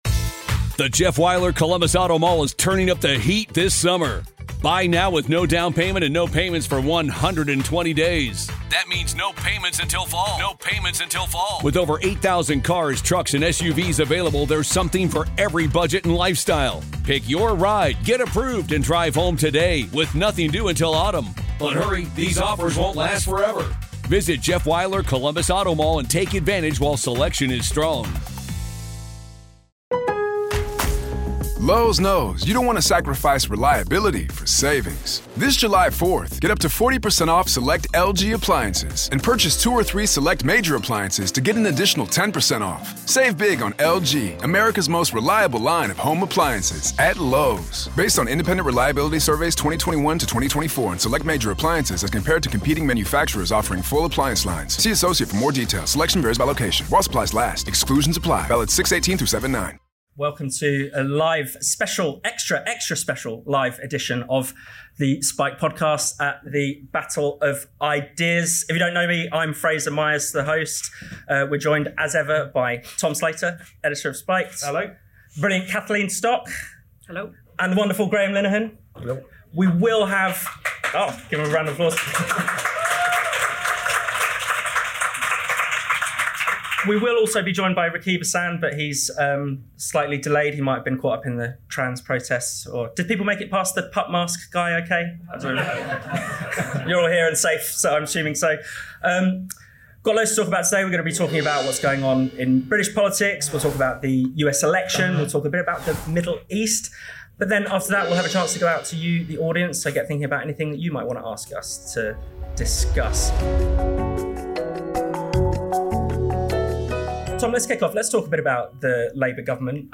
Live at the Battle of Ideas 2024